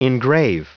Prononciation du mot engrave en anglais (fichier audio)
engrave.wav